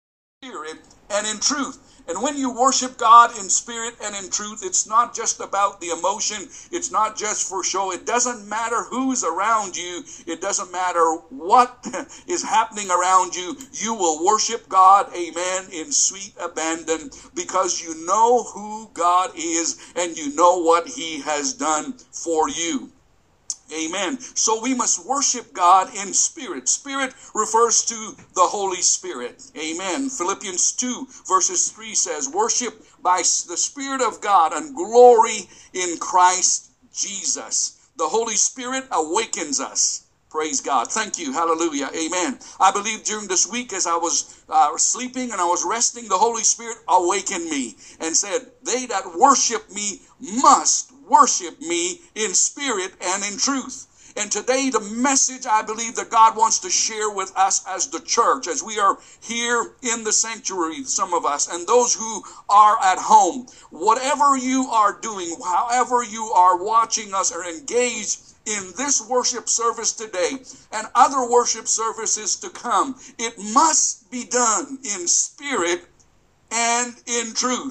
Sermons - Abundant Life
Pastor-preaching-audio-clip.m4a